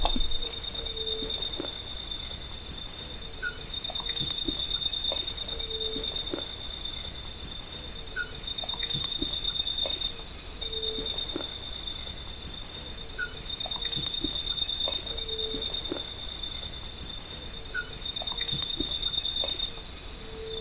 Bare-throated Bellbird (Procnias nudicollis)
Country: Paraguay
Location or protected area: Mbaracayú--Jejuimi
Condition: Wild
Certainty: Recorded vocal